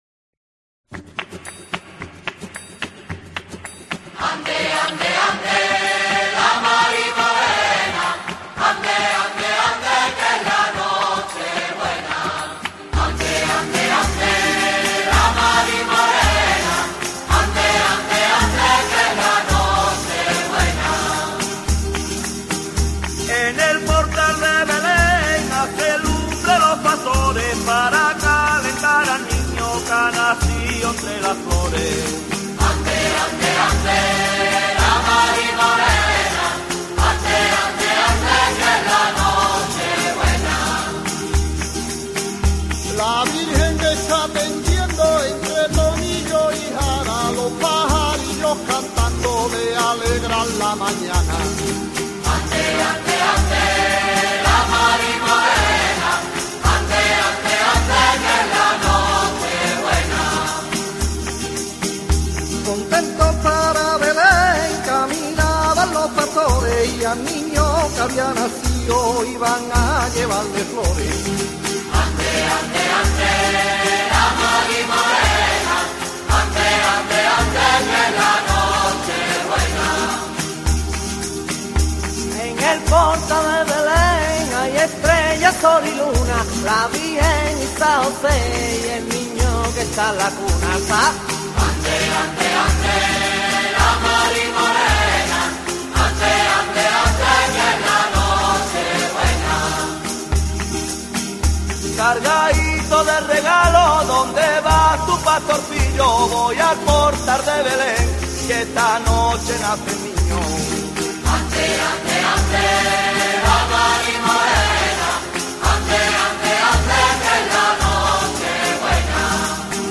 El villancico más rociero de 'La Marimorena'